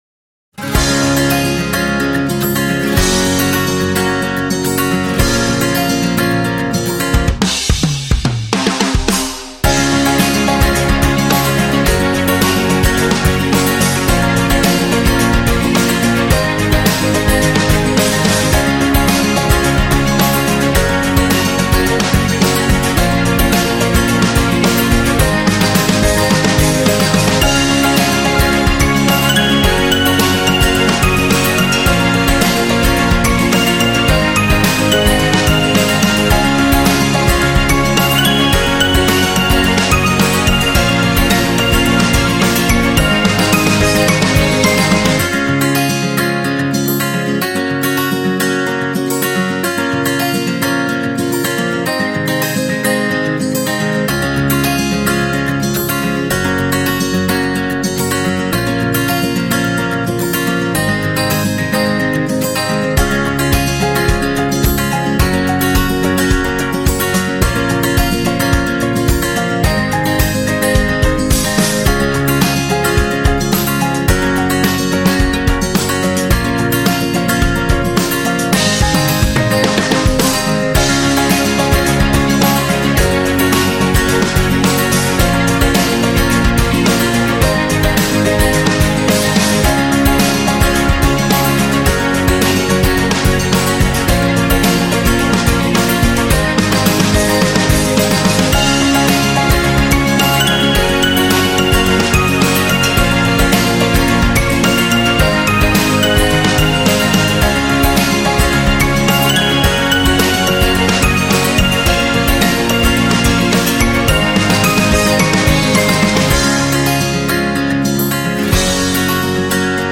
Acoustic